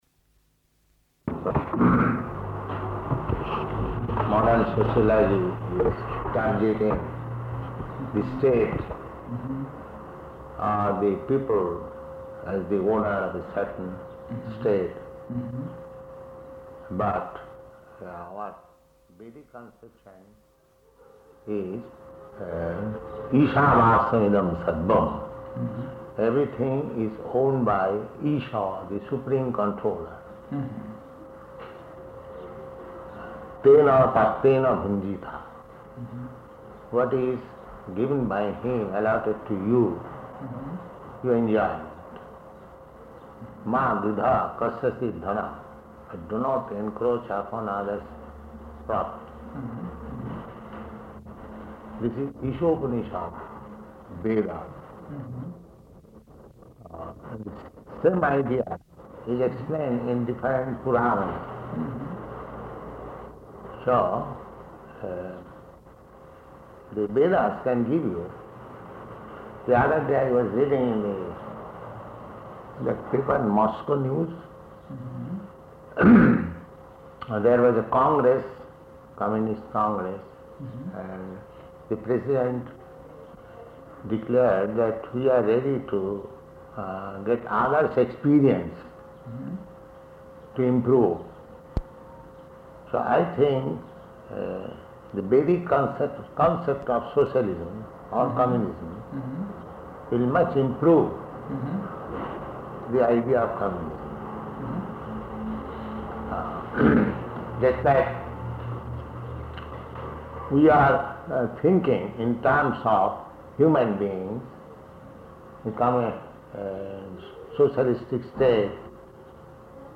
-- Type: Conversation Dated: June 22nd 1971 Location: Moscow Audio file: 710622R1-MOSCOW.mp3 Note: Audio has speed fluctuations throughout.